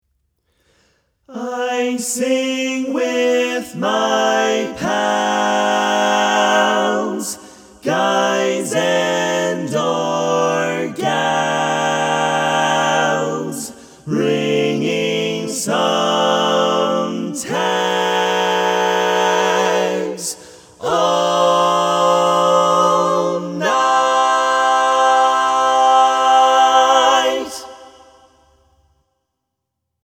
Key written in: C Major
How many parts: 4
Type: Barbershop
All Parts mix:
Learning tracks sung by